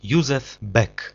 Józef Beck (Polish: [ˈjuzɛv ˈbɛk]